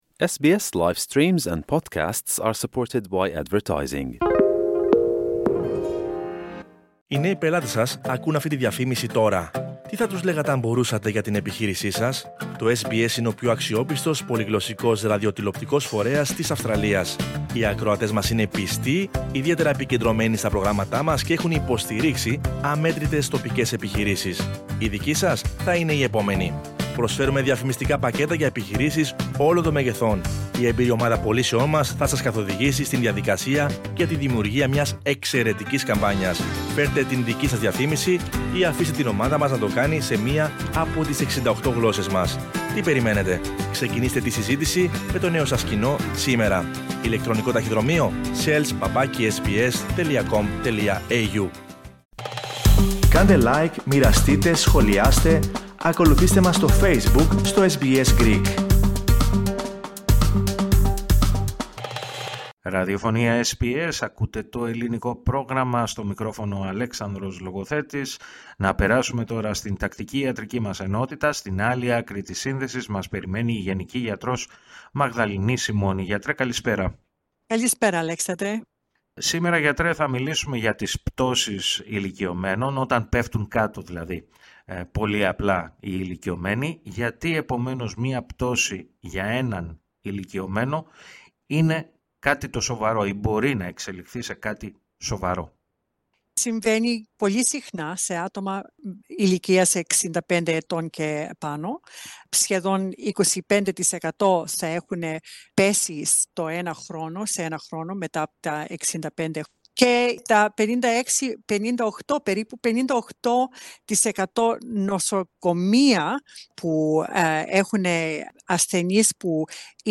Η γενική γιατρός